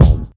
KICKSTACK2-R.wav